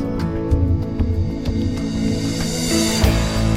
- Wie nennt sich denn so eine anschwellende Crash im Fachjargon?